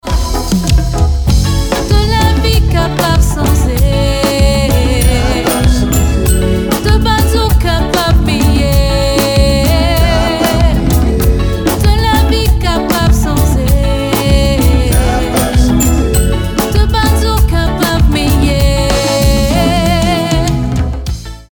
Clavier & Arrangeur
Basse
Guitare
Batterie & Percussions